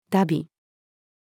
荼毘-female.mp3